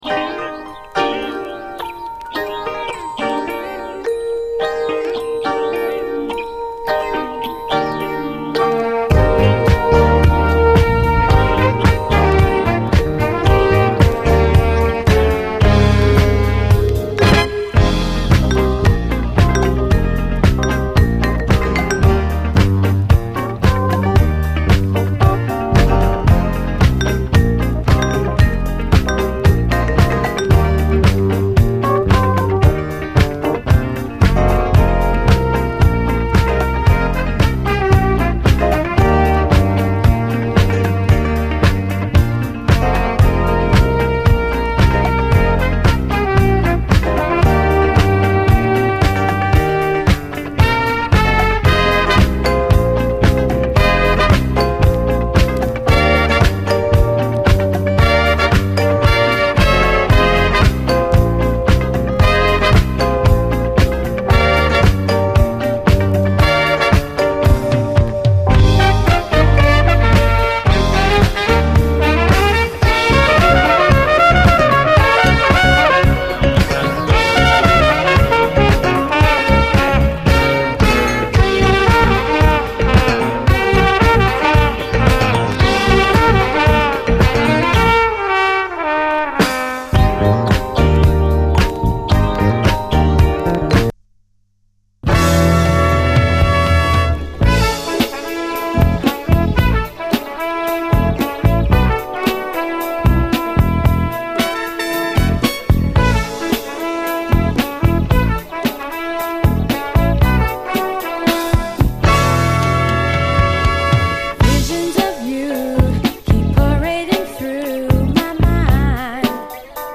SOUL, 70's～ SOUL, DISCO
ラヴリー・ソウル